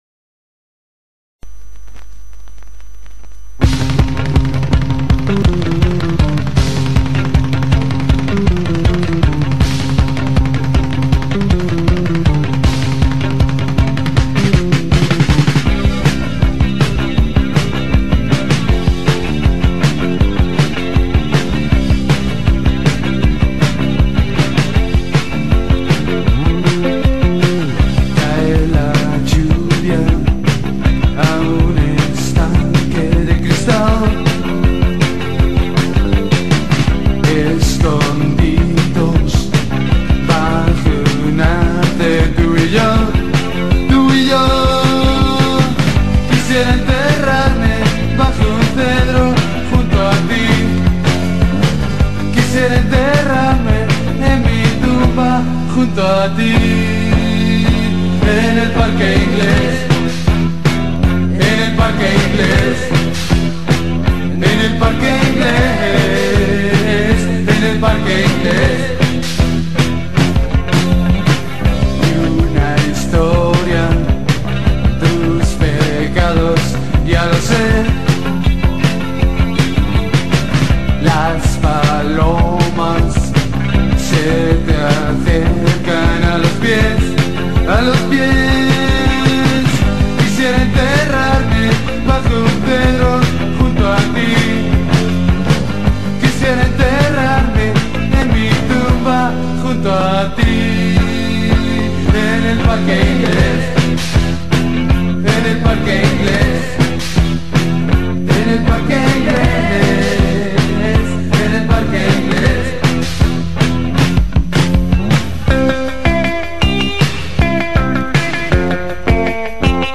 guitar and vocals
bass and backing vocals
drums
The songs were recorded at Audiofilm studios in Madrid.